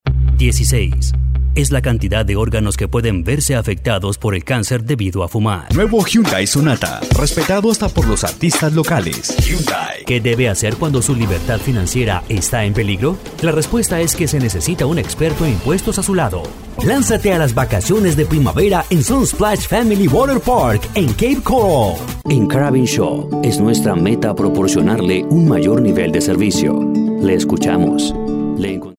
Male
Authoritative, Character, Cool, Corporate, Natural, Young, Bright, Engaging, Friendly, Smooth
Neutral, Latin American, Colombian, USA, Mexican neutral
CORPORATE NARATION.mp3
Microphone: Neumann